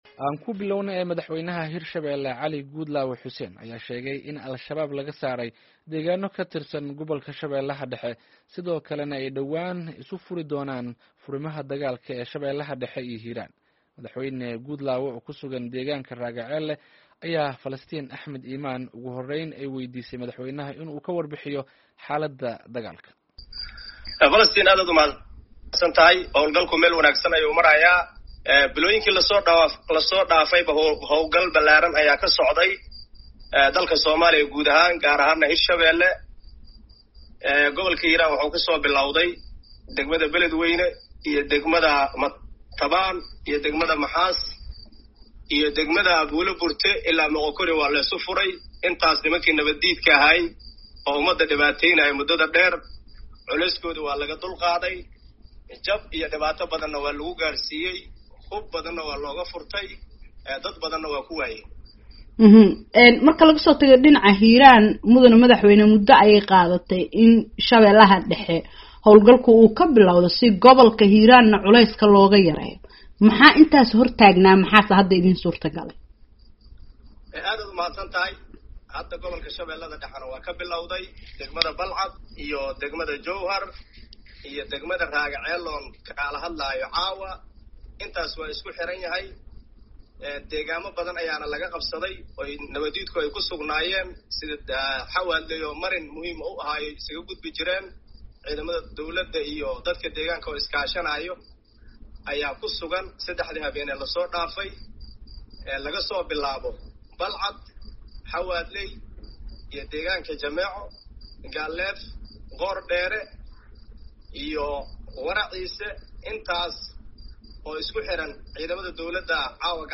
Wareysiga madaxweyne Guudlaawe.mp3